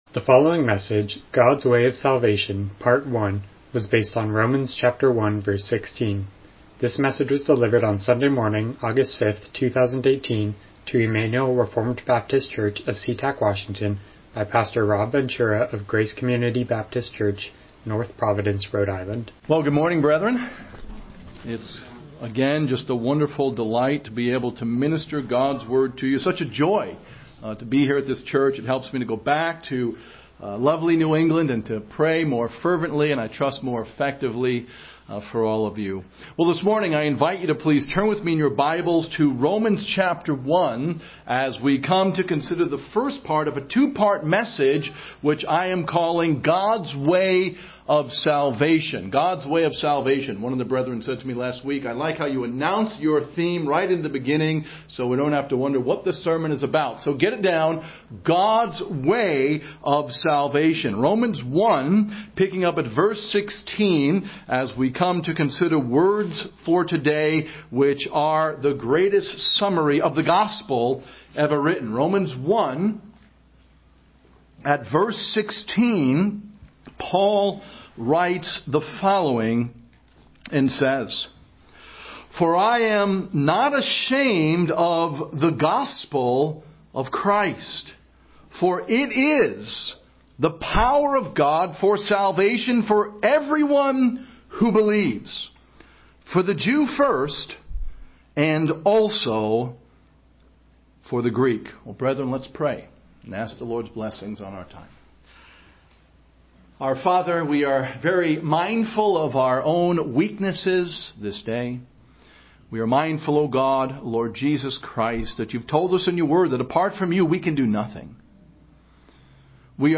Miscellaneous Passage: Romans 1:16 Service Type: Morning Worship « The Glorious Gospel Message